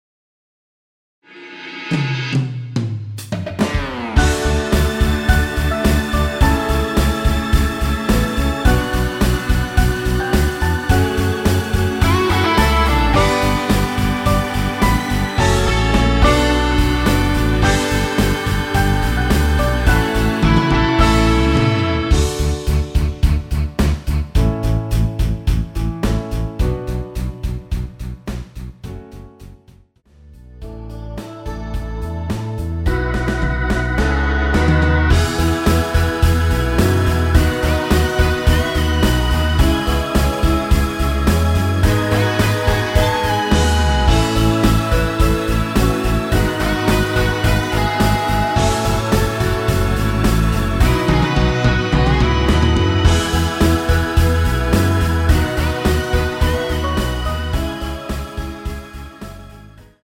원키에서(+2)올린 MR입니다.
앞부분30초, 뒷부분30초씩 편집해서 올려 드리고 있습니다.
중간에 음이 끈어지고 다시 나오는 이유는